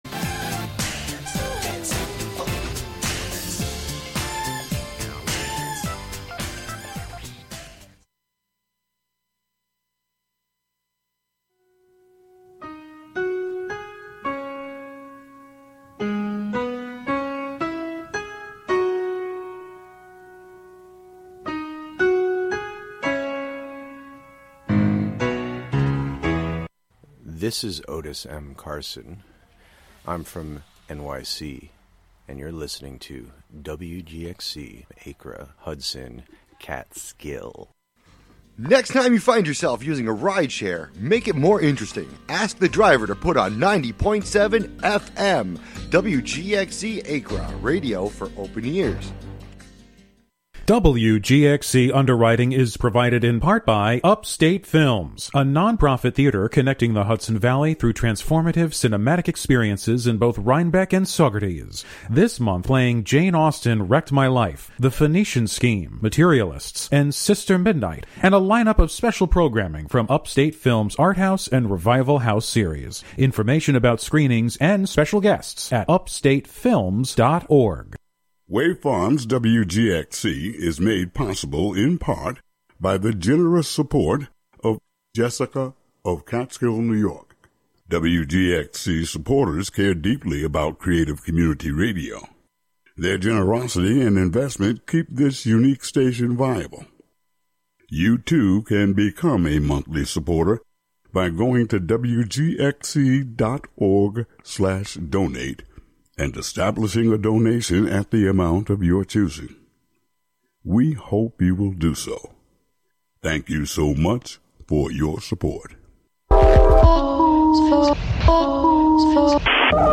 Tune in for special fundraising broadcasts with WGXC Volunteer Programmers!